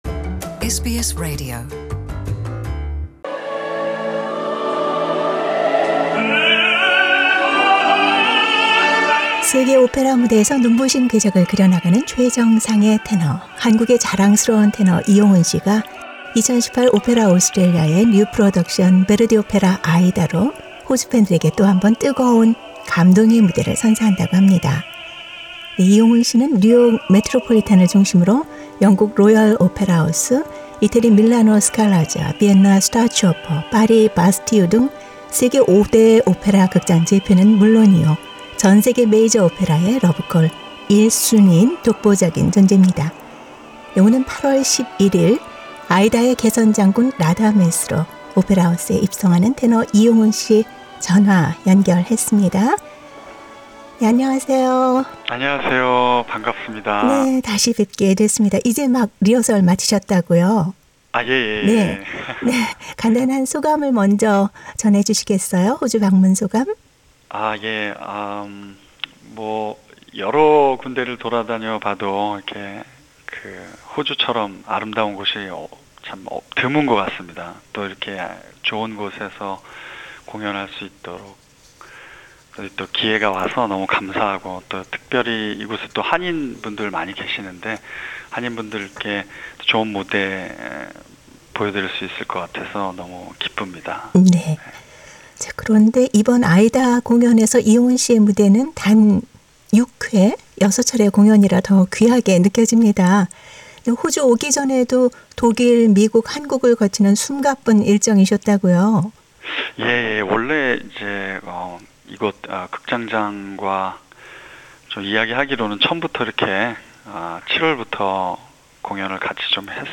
SBS Radio 한국어 프로그램이 8월 11일 <아이다> 오페라하우스 공연을 앞두고 테너 이용훈과의 전화 대담을 가졌다. 그의 음악의 중심이 되는 진솔한 삶과 가족애를 들어본다.